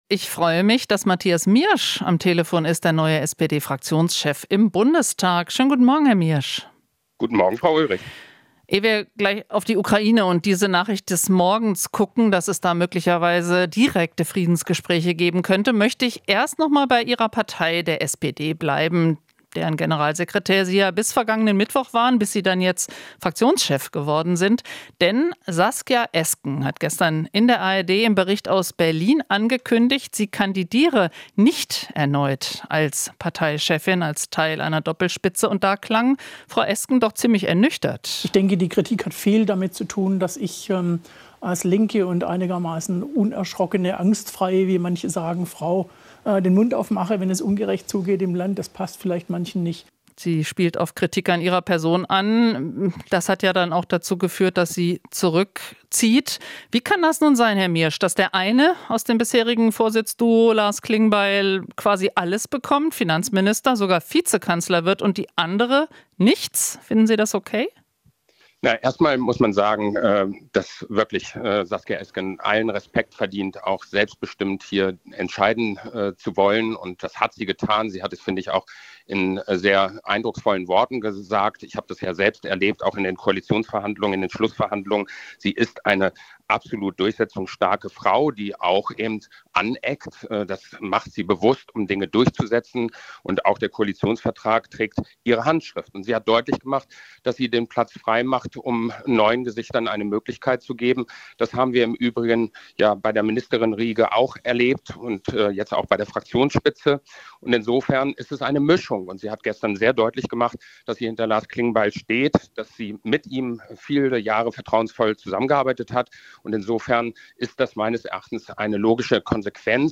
Interview - Miersch (SPD): Alle Möglichkeiten für Ukraine ausloten